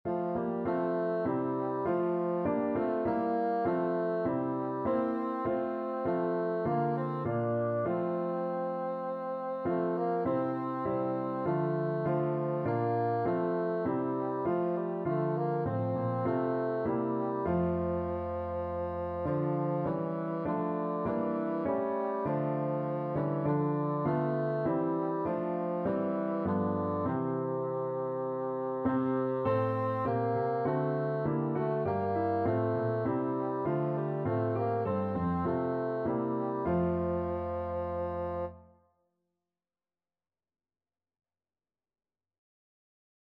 Christmas
C4-D5
4/4 (View more 4/4 Music)
Classical (View more Classical Bassoon Music)